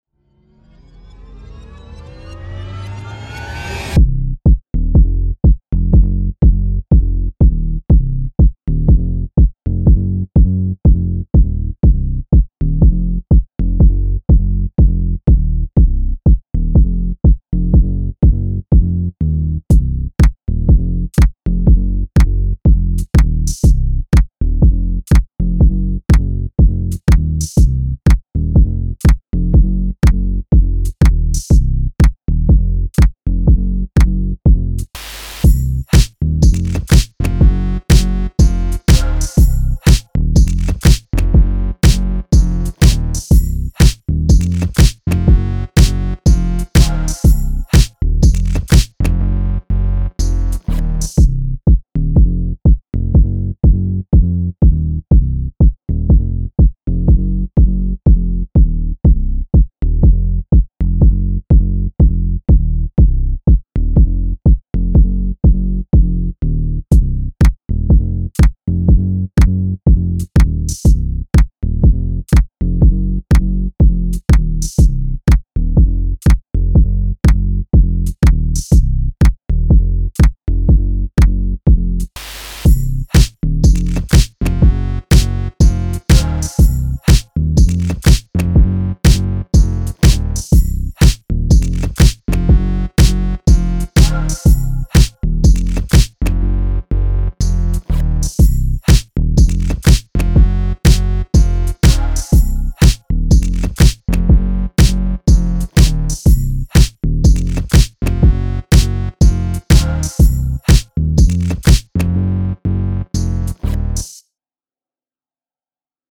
Pop
fMinor